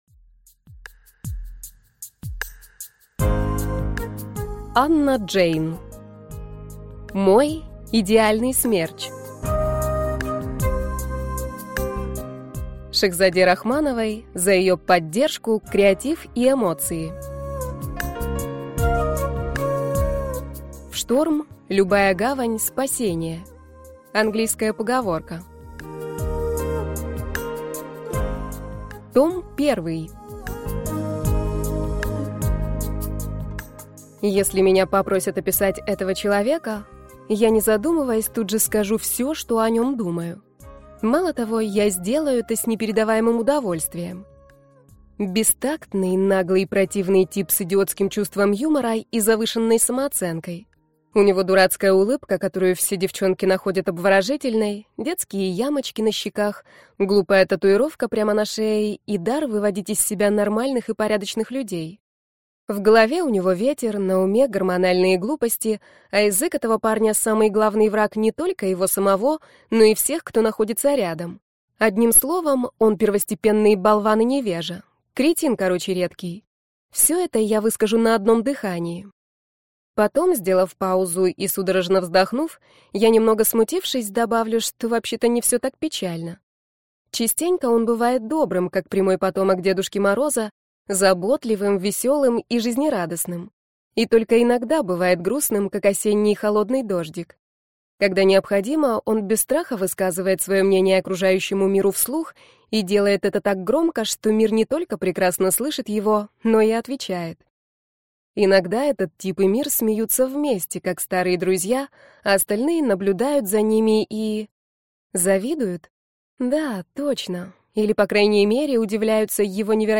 Аудиокнига Мой идеальный смерч. Трилогия в одном томе | Библиотека аудиокниг